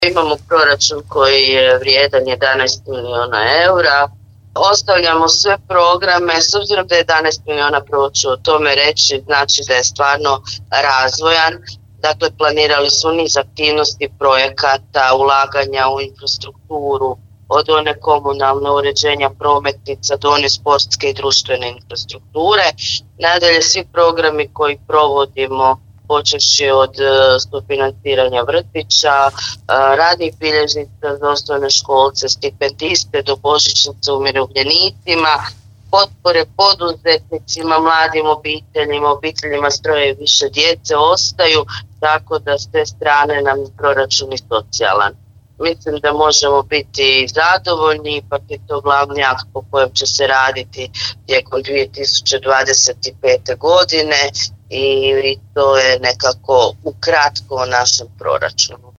Proračun grada Pakraca za 2025. usvojen je na jučerašnjoj sjednici Gradskog vijeća Grada Pakraca većinom glasova. Kroz najvažniji financijski dokument ogleda se razvojna i socijalna politika Grada Pakraca u sljedećoj godinu koju će u kratkim crtama obrazložiti predlagateljica, gradonačelnica Pakraca Anamarija Blažević: